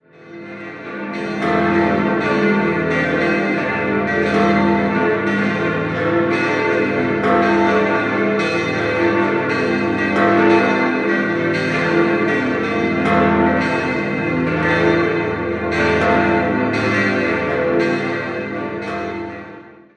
6-stimmiges Geläut: f°-a°-c'-d'-e'-g' Glocke 3 wurde 1876 von Jakob Keller in Unterstrass gegossen, Glocke 5 im Jahr 1575 von Peter Füssli in Zürich.